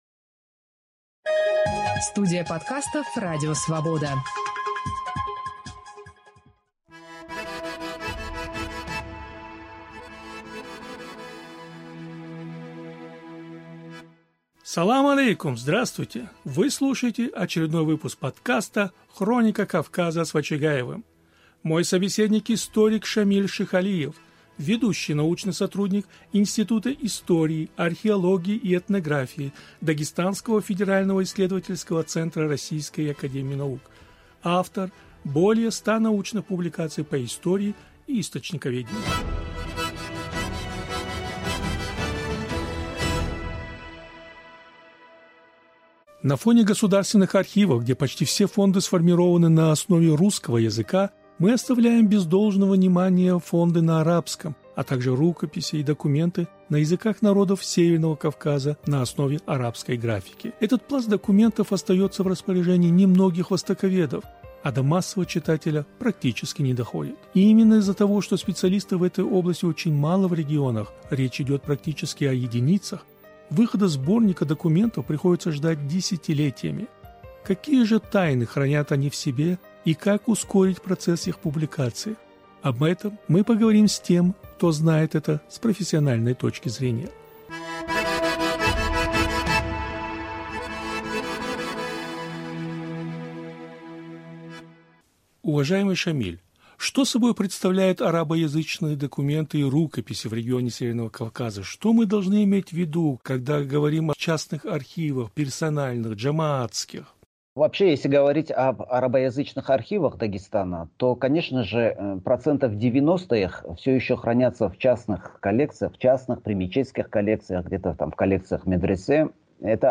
беседует с историком